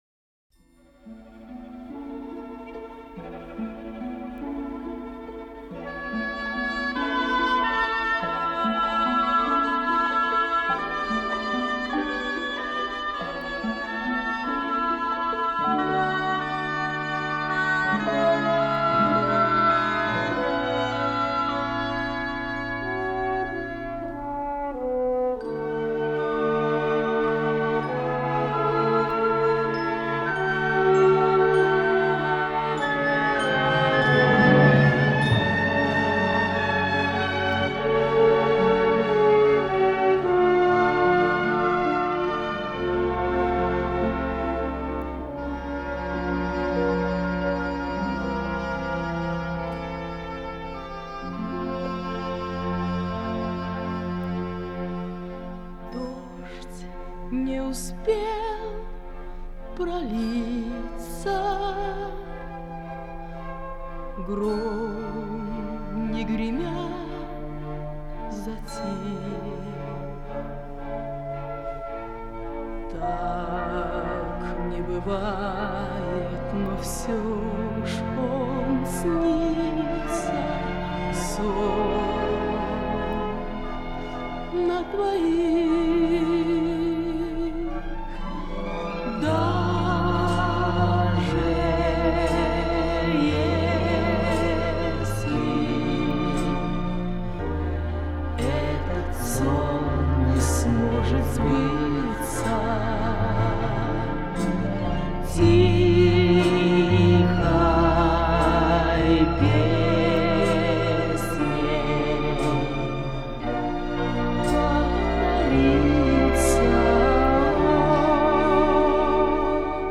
рок-оперы